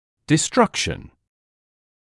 [dɪ’strʌkʃn][ди’стракшн]разрушение